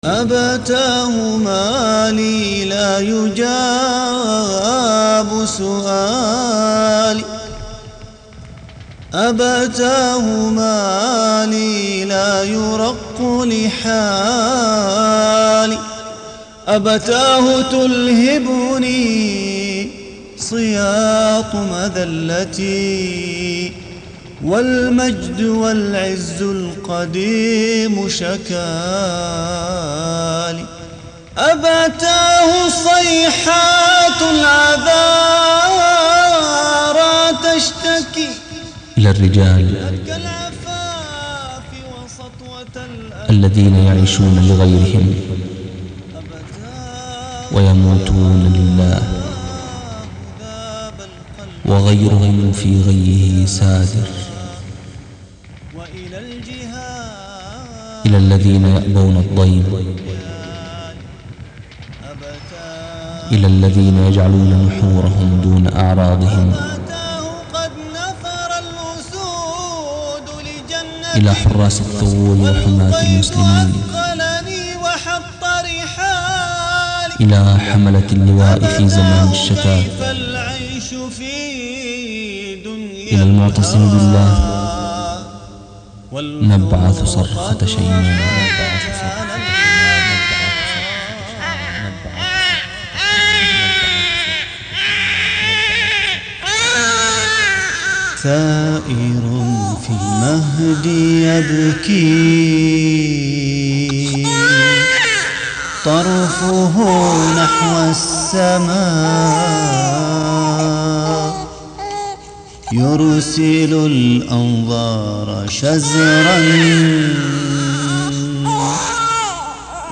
نشيد Tags